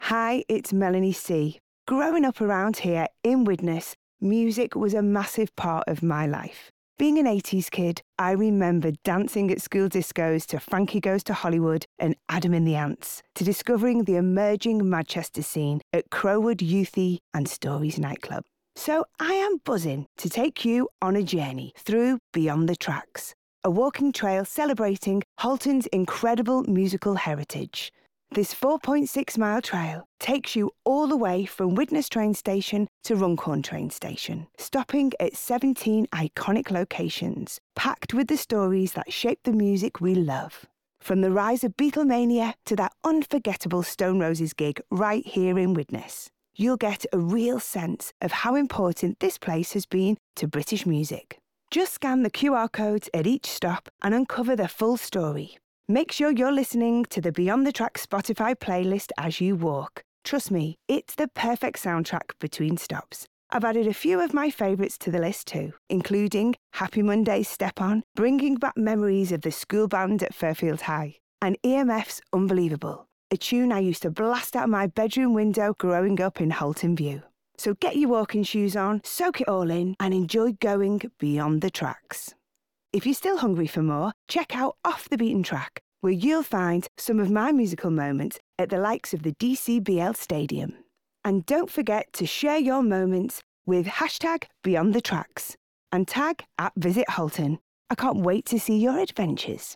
Welcome to Beyond the tracks, click here to listen to an introduction to our musical story from Melanie C
Beyond The Tracks Introduction from Melanie C.mp3